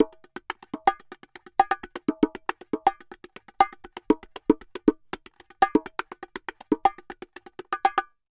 Bongo_Salsa 120_3.wav